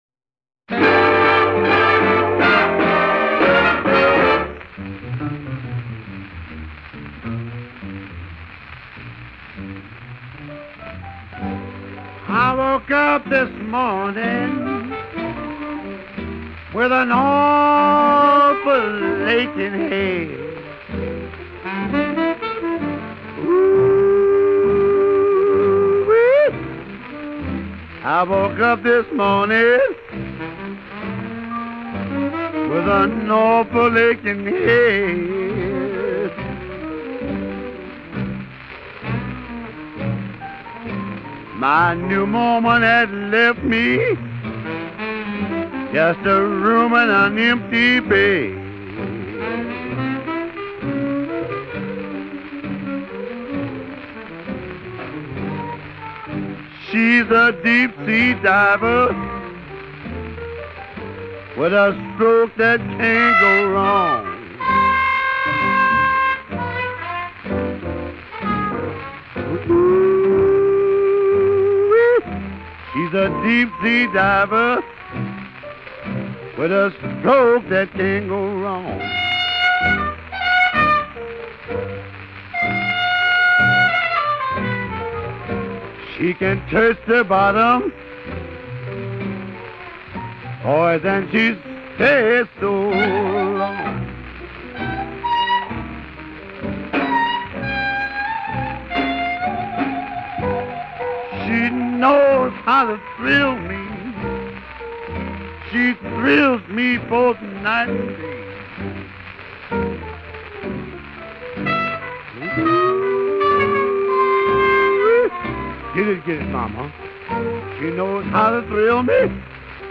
as blues & jazz singer is more correct
More Music...all as featured vocalist